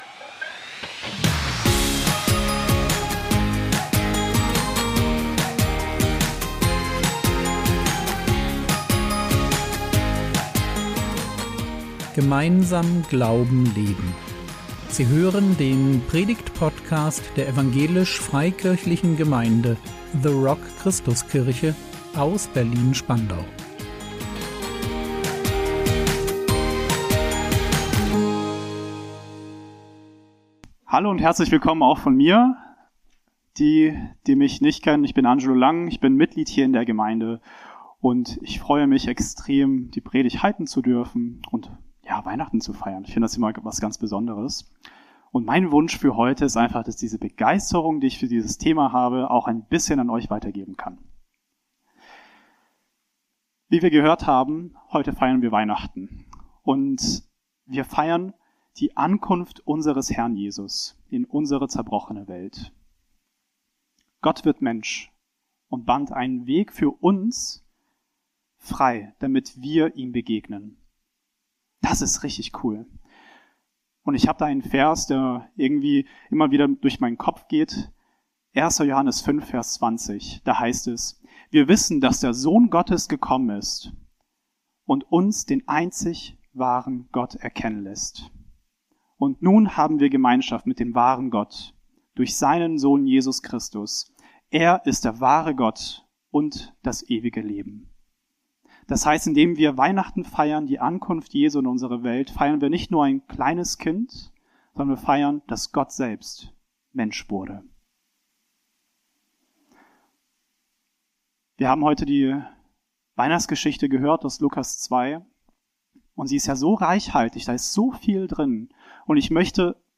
Weihnachtspredigt